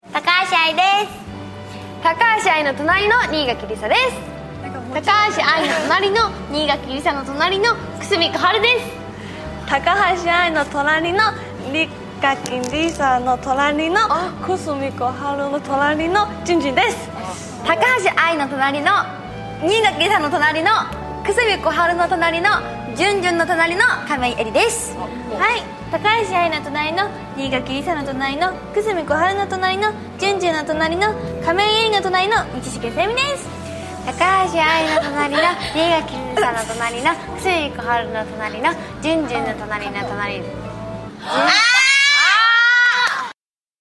nagai_jiko_shoukai_momusu_1.mp3